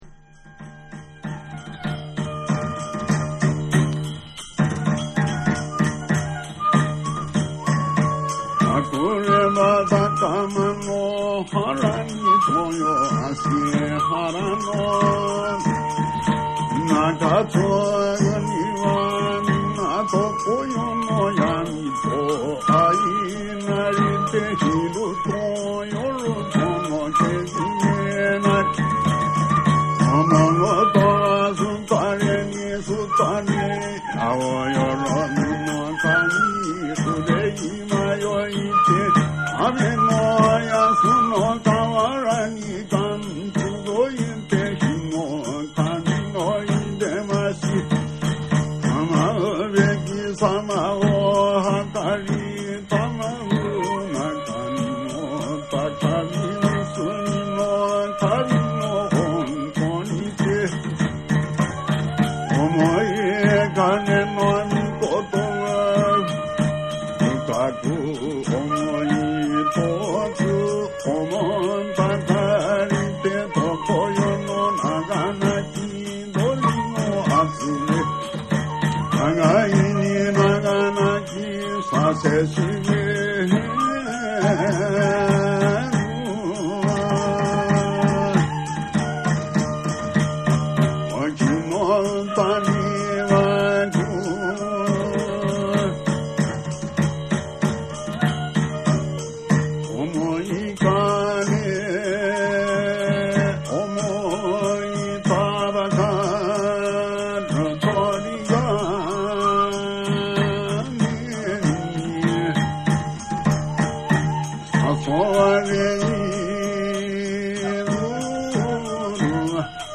これは広島県に伝わる日本最古の神楽（かぐら） である。